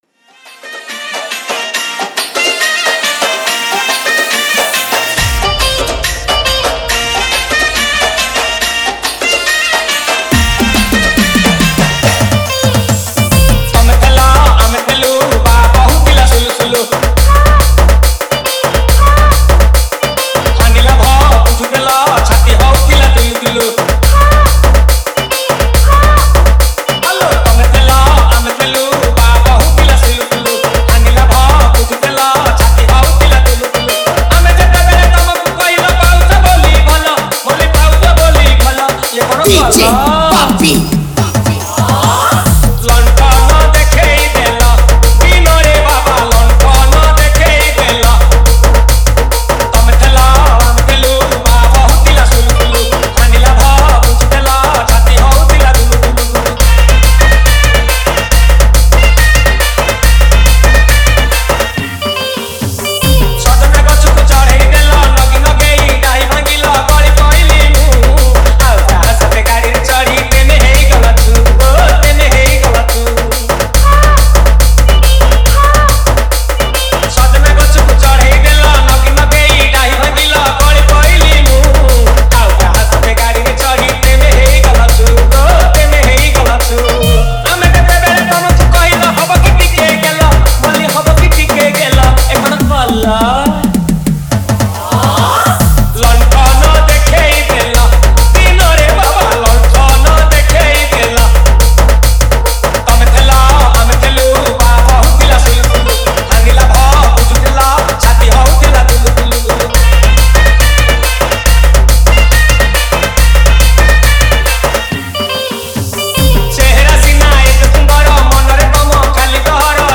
Category : Trending Remix Song